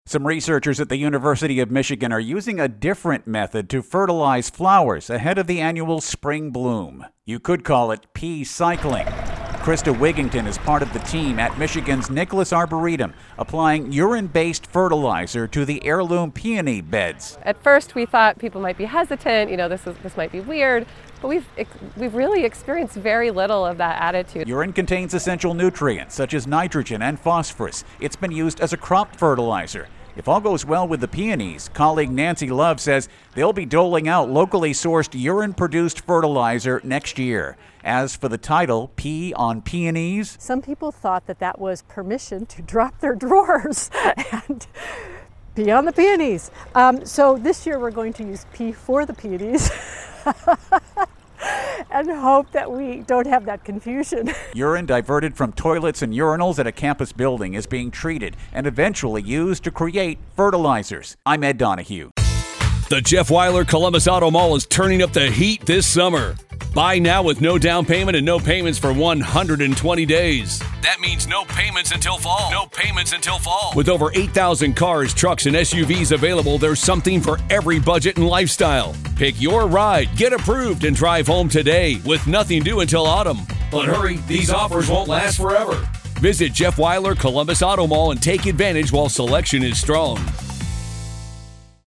Pee-cycling wrap with intro